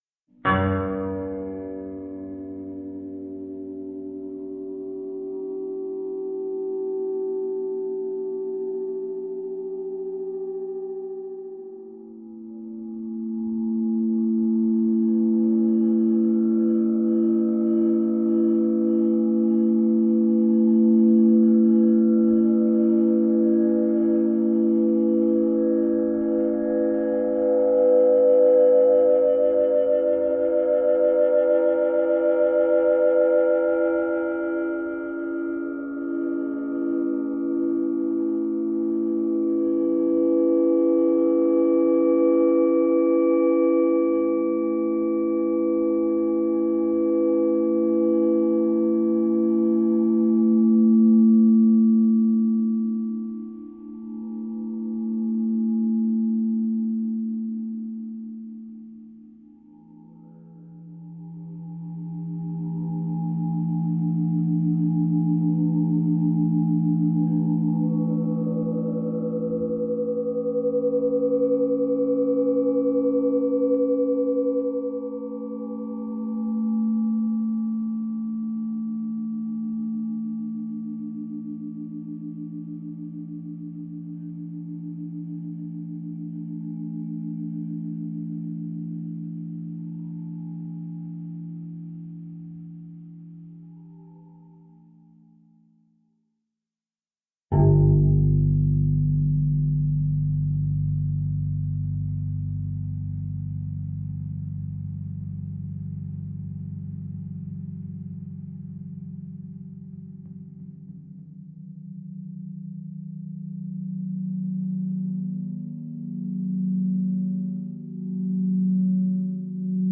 Musique Eolienne
4:30 - harpes éoliennes à l'aurore
4:30 - aeolians harps at sunset